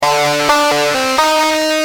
Lead_a7.wav